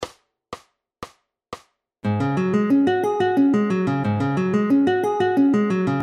F♯sus2 トライアド
コンテンポラリー,ジャズギター,トライアド,sus2,アドリブ